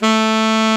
SAX A.MF A03.wav